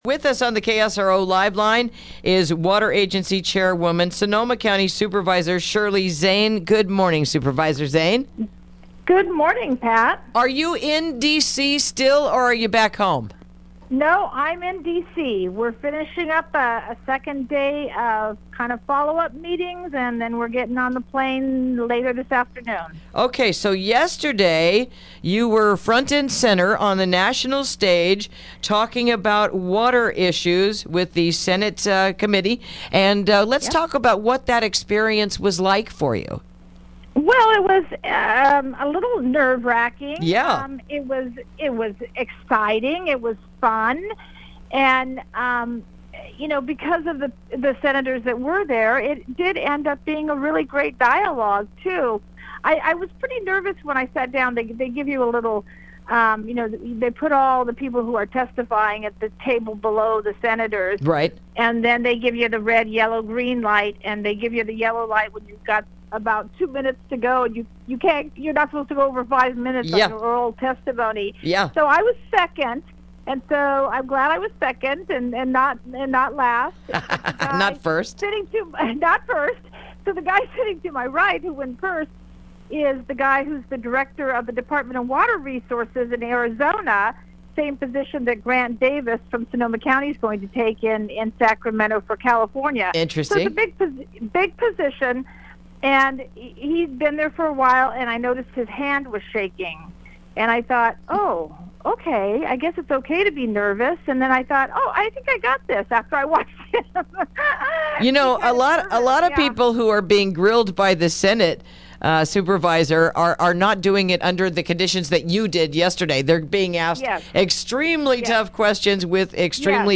Interview: Water Agency Chairwoman Testifies on Water Initiatives